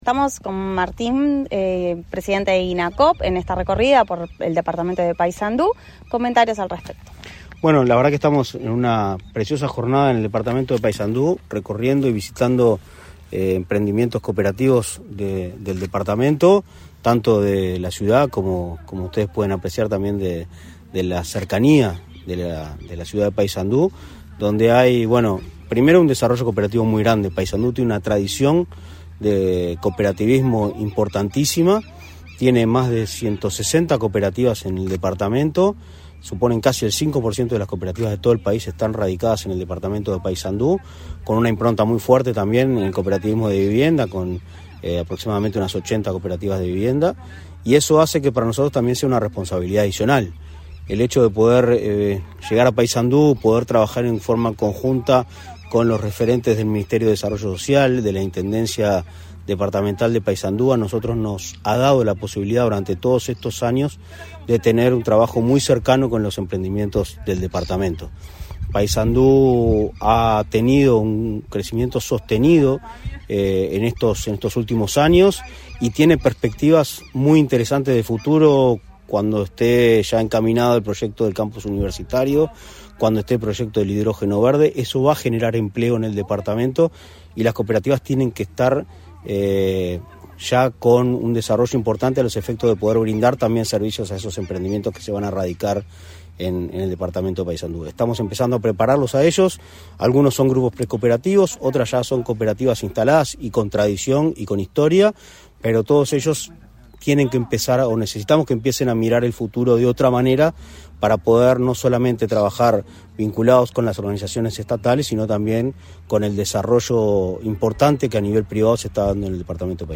Entrevista al presidente del Inacoop, Martín Fernández